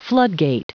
Prononciation du mot floodgate en anglais (fichier audio)
Prononciation du mot : floodgate